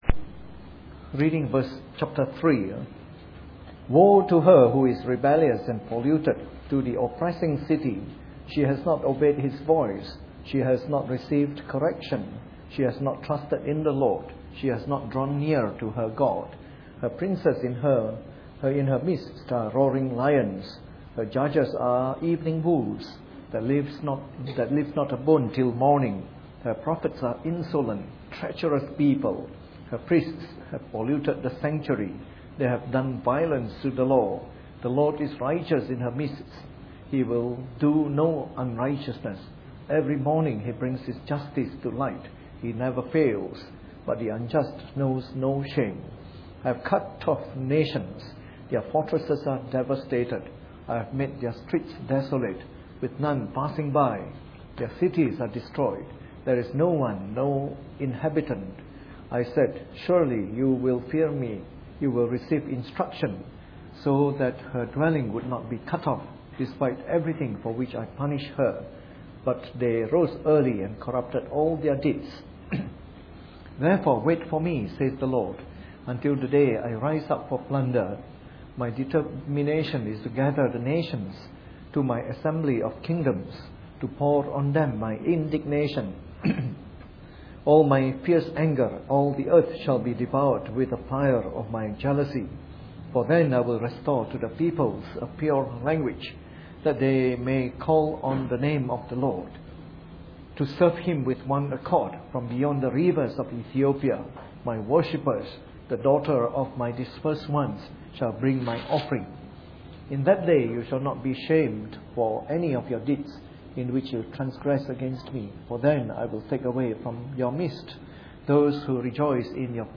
Preached on the 23rd of January 2013 during the Bible Study, from our series on “The Minor Prophets.”